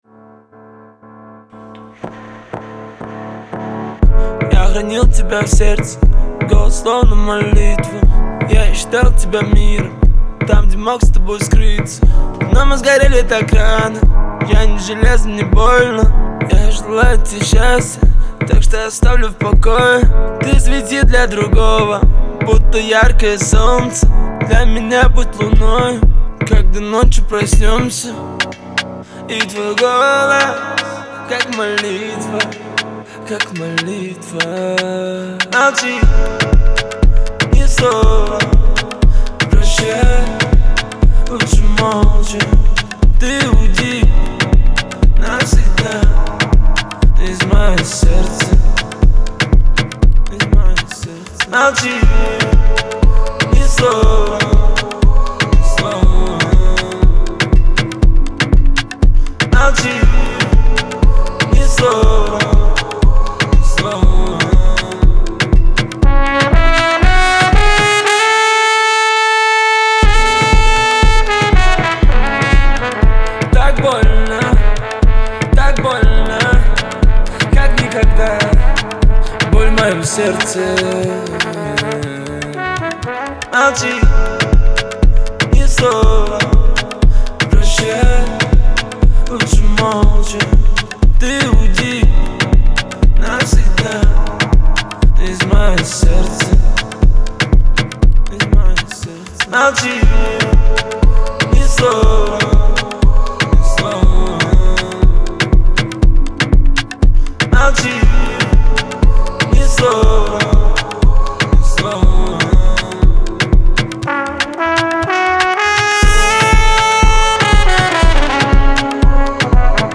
это проникновенная композиция в жанре хип-хоп